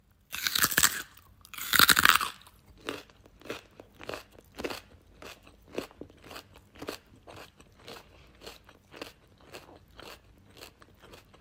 Хруст моркови при кусании, жевание, нарезка и другие звуки в mp3 формате
2. Человек откусил морковь и пережевывает с хрустом